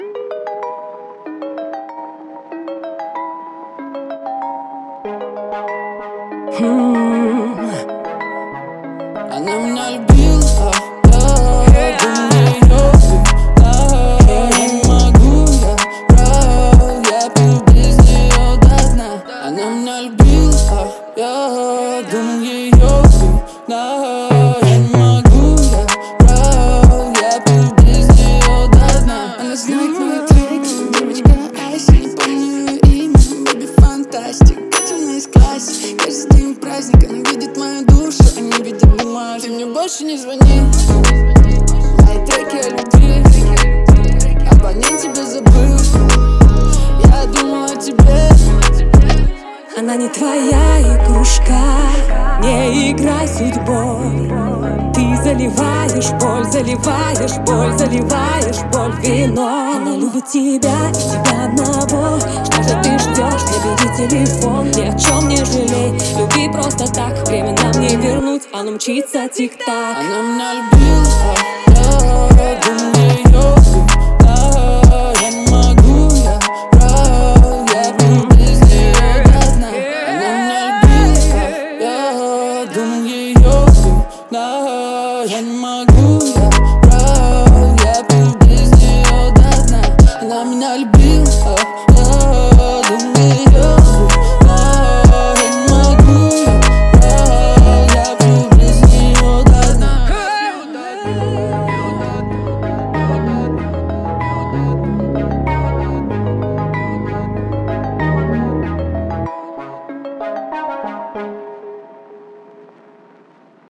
Звучание трека отличается мелодичностью и ритмичными битами